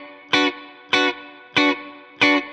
DD_StratChop_95-Bmin.wav